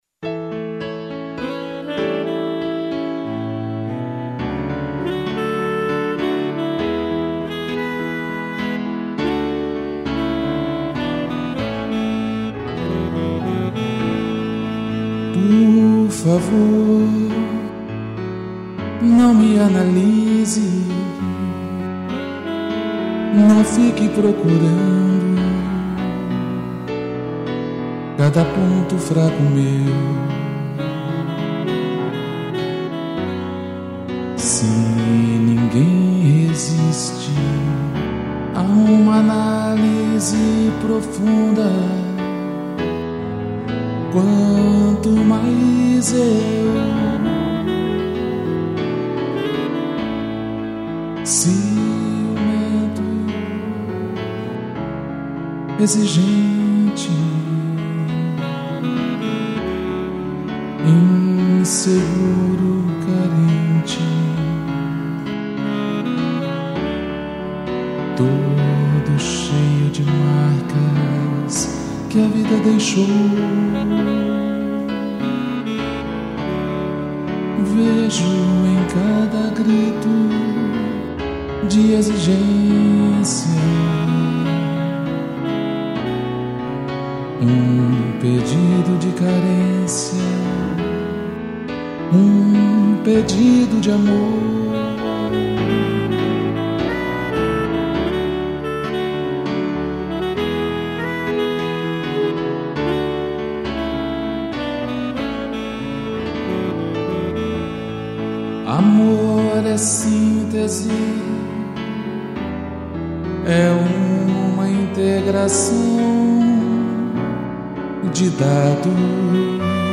piano, sax e cello